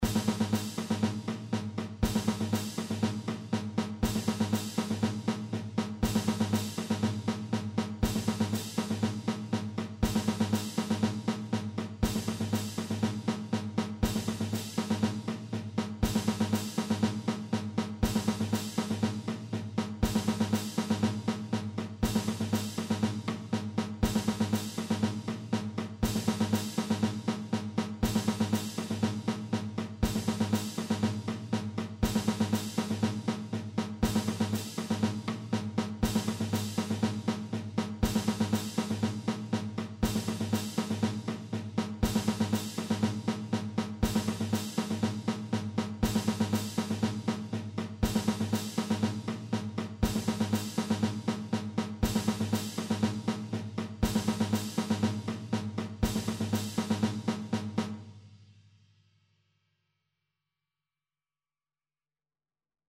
Áudio para marcação de ritmo (120 BPM).